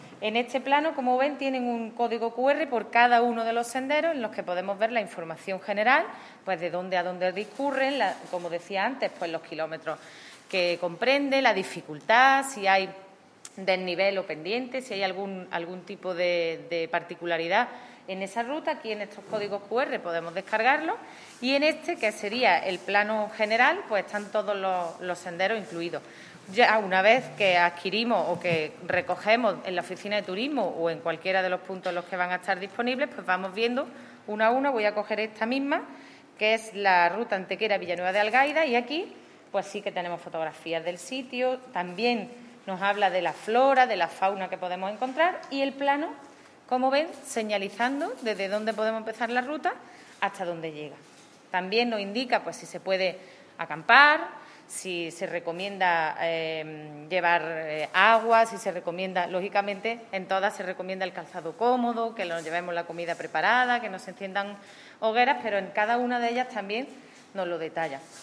La teniente de alcalde delegada de Turismo, Ana Cebrián, ha presentado en la mañana de hoy los nuevos folletos editados por el Ayuntamiento para promocionar y poner en valor la Red de Senderos de Antequera, que agrupa a diez de los itinerarios más atractivos y conocidos de nuestro municipio.
Cortes de voz